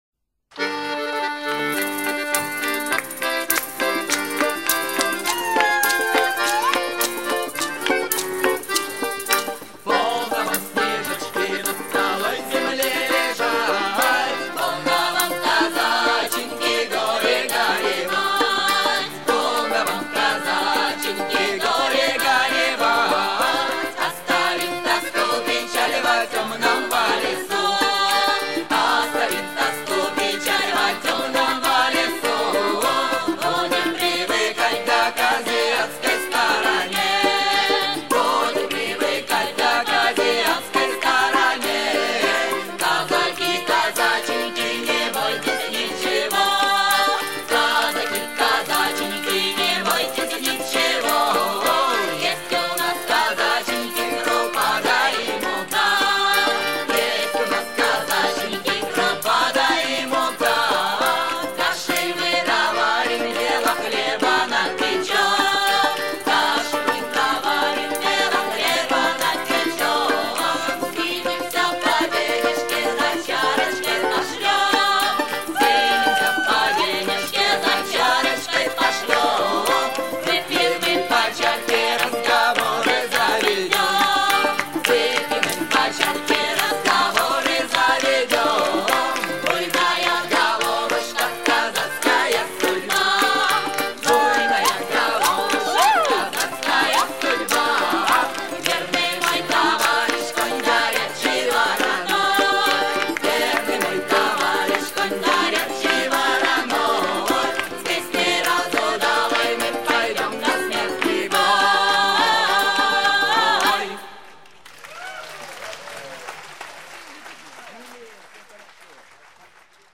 Kazatskaia pesnia Détails de l'archive Titre Kazatskaia pesnia Origine du titre : Editeur Note groupe de Saint-Pétersbourg venu par hasard à la fête de Douarnenez Ils donneront deux concerts improvisés.